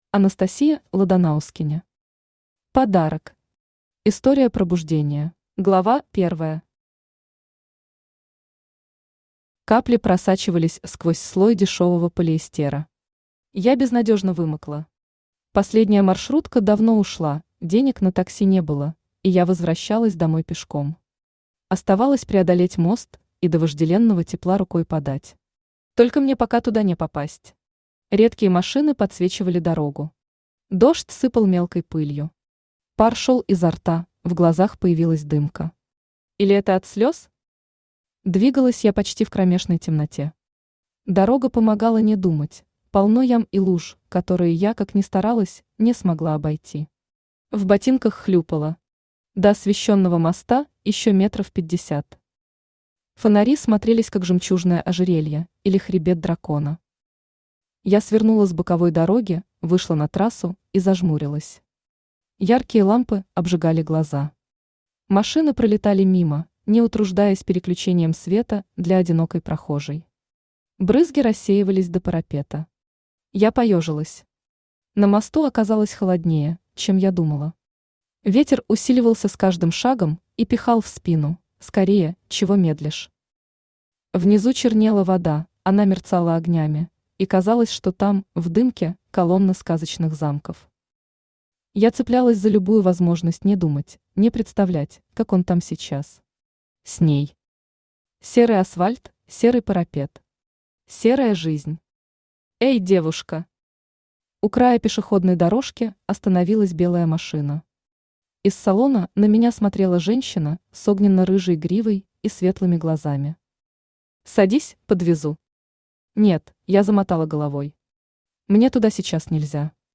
Аудиокнига Подарок. История пробуждения | Библиотека аудиокниг
История пробуждения Автор Анастасия Ладанаускене Читает аудиокнигу Авточтец ЛитРес.